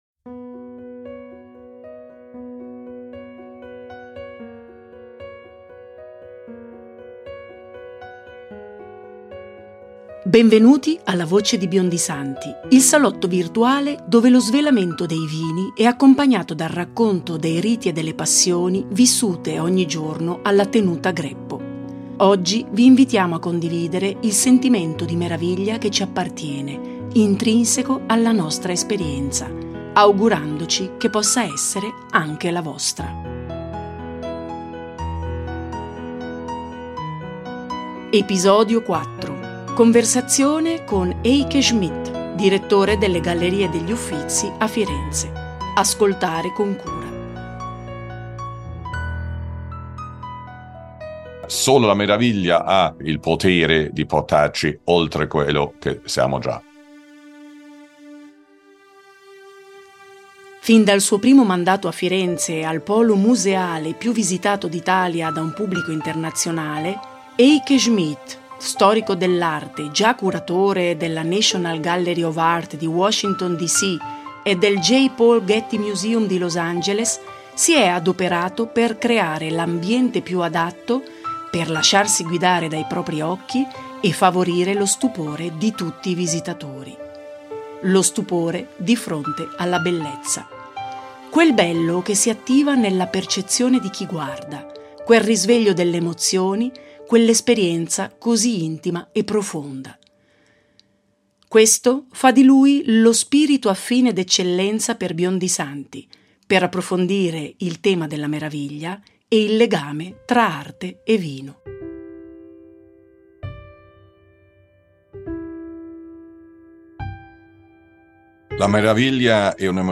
Conversazione con Eike Schmidt, Direttore delle Gallerie degli Uffizi a Firenze.
it-ep4-eike-schmidt.mp3